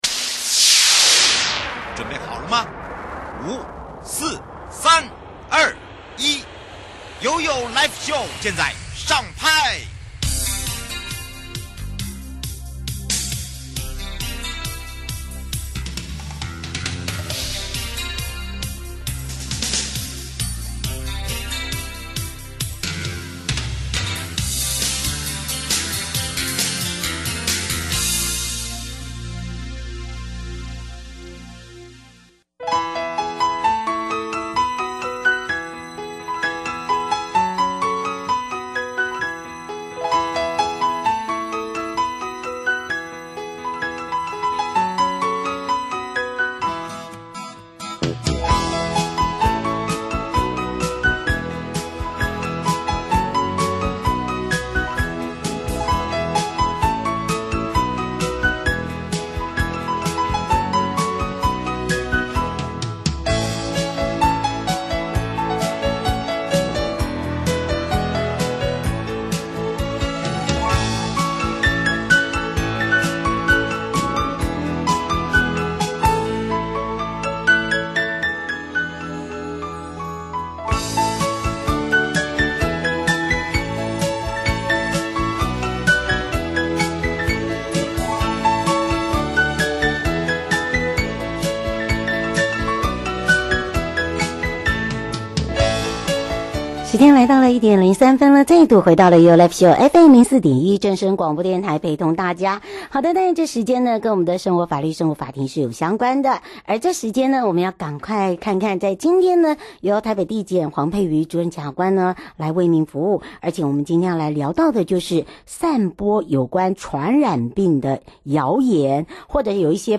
受訪者： 台北地檢 黃珮瑜主任檢察官 節目內容： 1. 散播有關傳染病之謠言或不實訊息,有刑責嗎?嚴重特殊傳染性肺炎是否為法定傳染病?如果知道自己已經罹患法定傳染病,卻仍在不依主管機關指示隔離治療,並因而傳染他人的話,法律責任為何? 2.